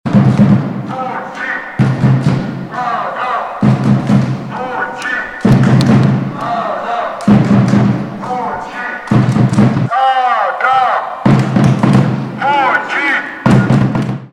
Całe wydarzenie uświetnili swoją oprawą kibice koszykarskiego Śląska Wrocław.